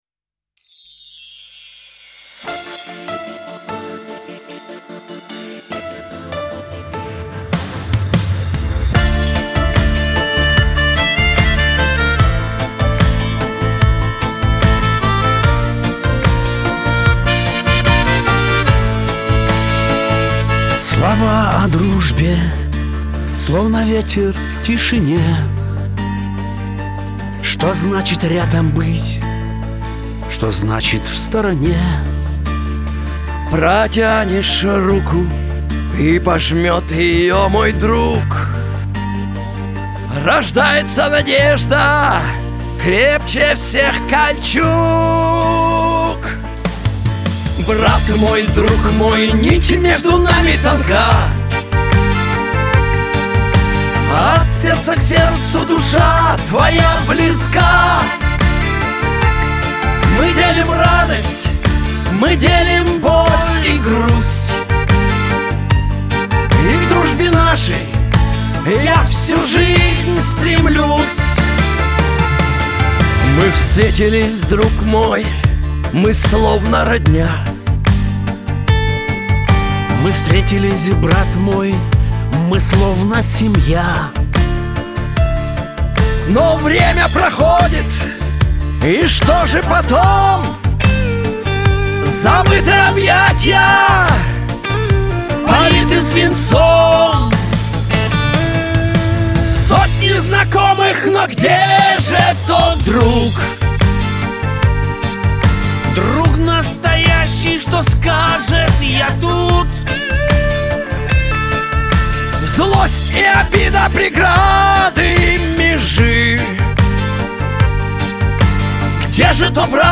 эстрада
pop
Шансон